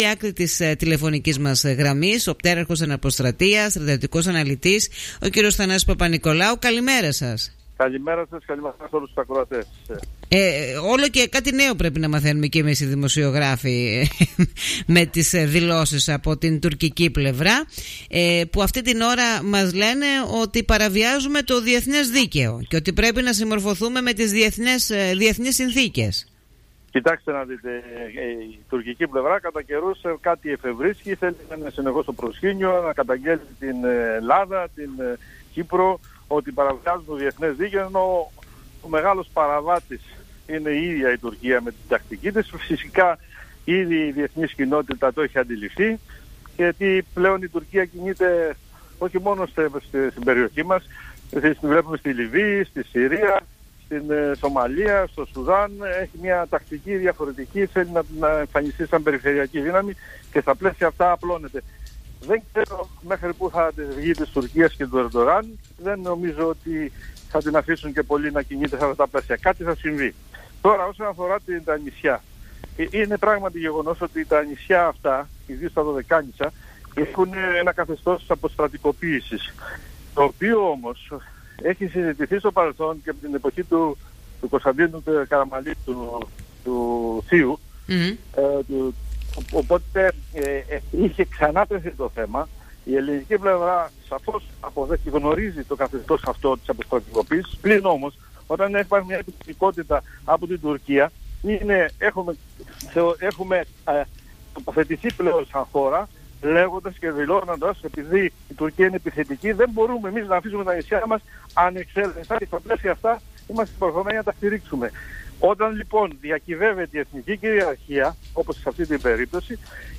μίλησε μέσα από την συχνότητα του Politica 89.8